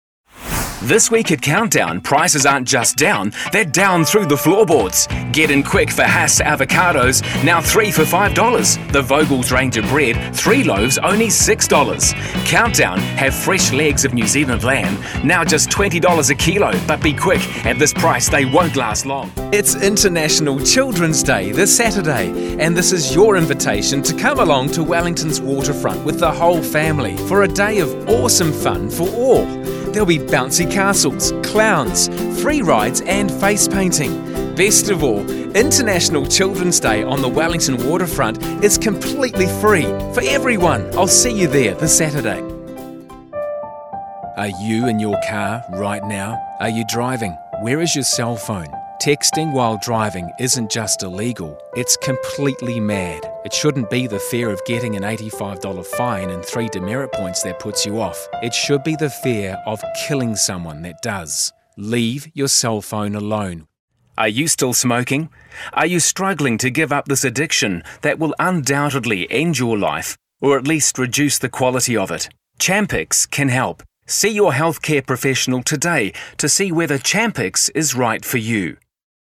Demo
Adult
Has Own Studio
English | New Zealand
commercial